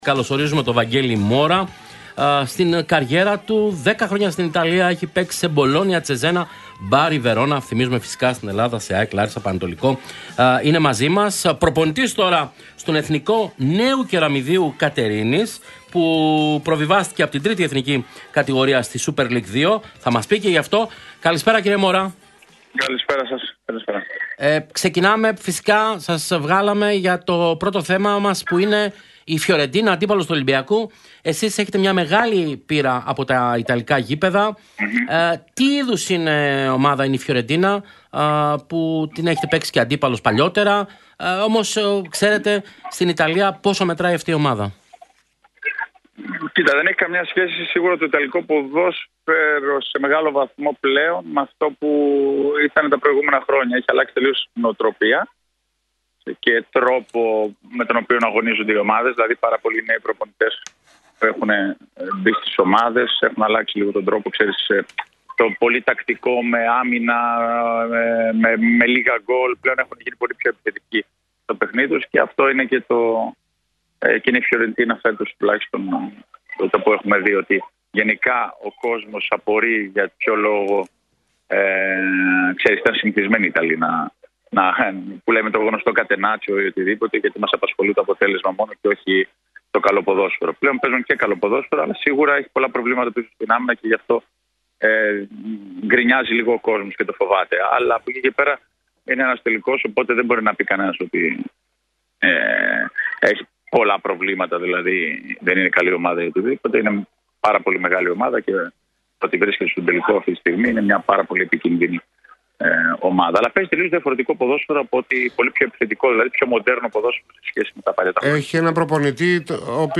Μιλώντας στην εκπομπή Real sports